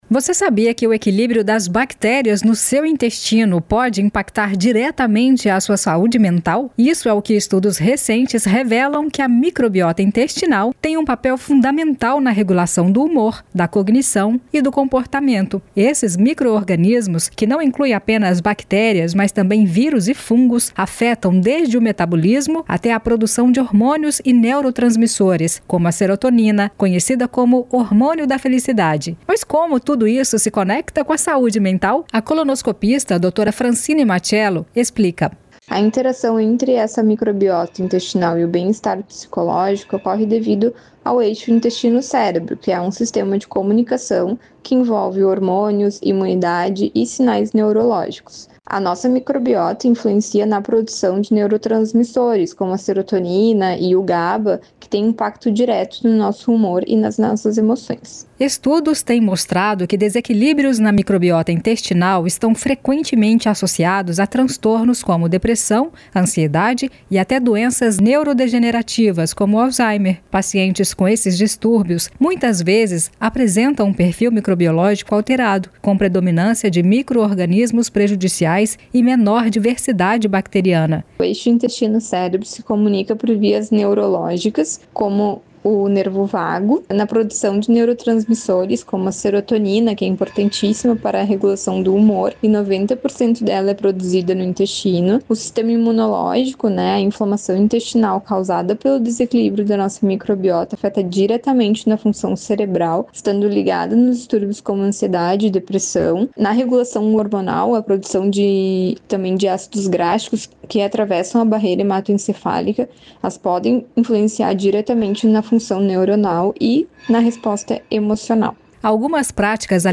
Entrevistada: